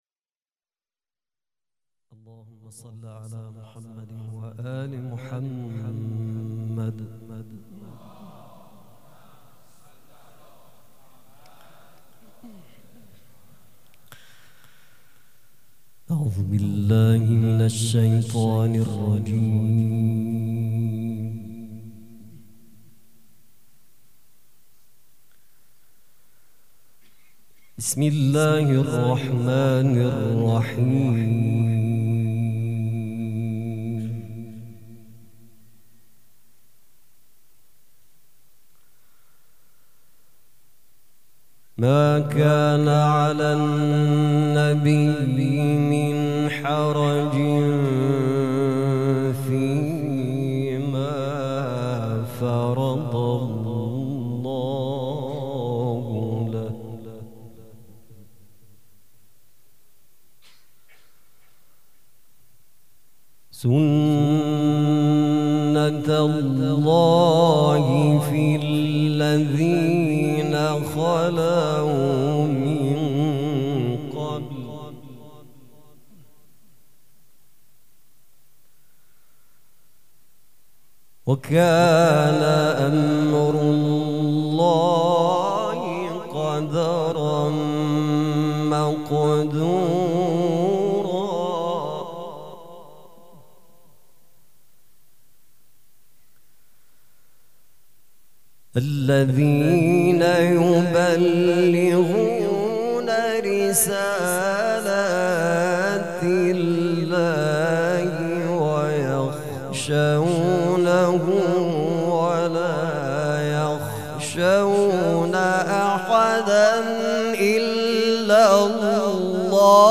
قرائت قرآن
مراسم میلاد پیامبر خاتم(صلی الله و علیه و آله)و حضرت امام جعفر صادق(ع) ۳شنبه ۱۴ آدر ماه ۱۳۹۶ هيئت ريحانة الحسين(سلام الله علیها)